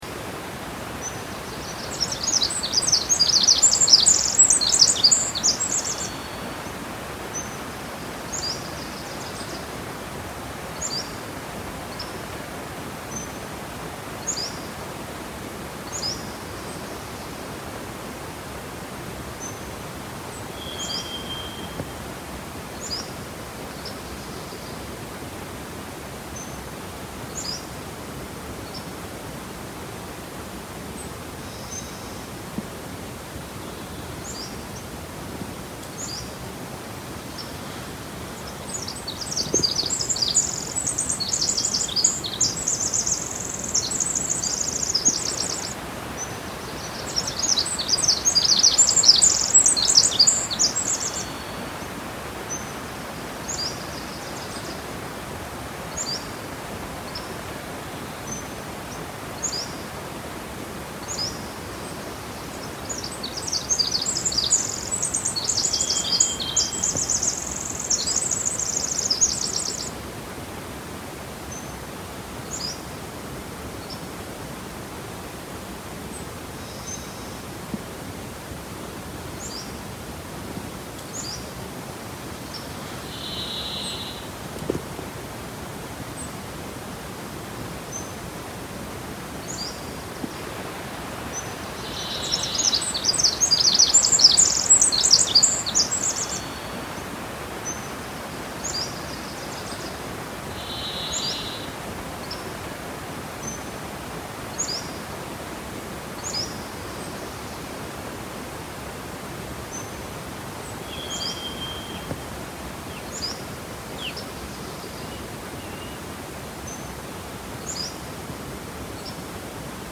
Jedidiah Smith Redwoods Soundscape — The Tree Pilgrimage
The Birds and River of Jedidiah Smith Redwoods
sounds of their environment including the river flowing nearby and various bird calls.